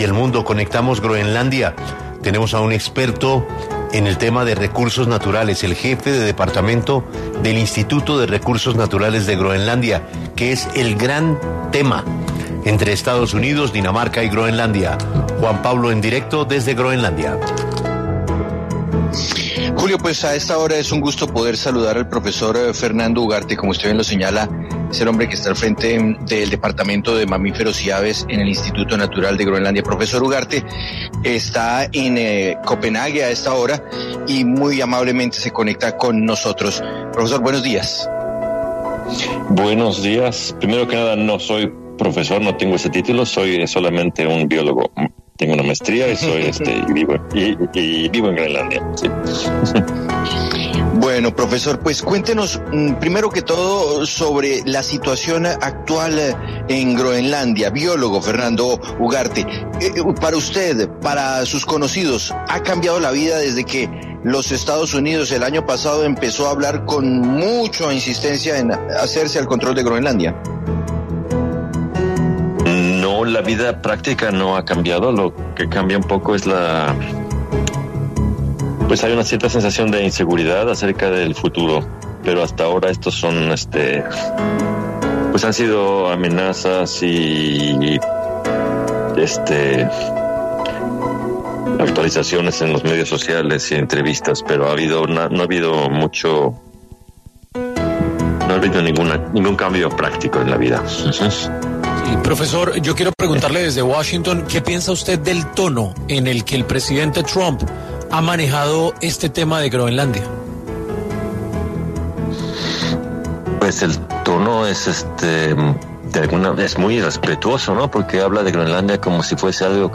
biólogo, pasó por los micrófonos de 6AM W de Caracol Radio para hablar sobre la relación de Groenlandia y Estados Unidos.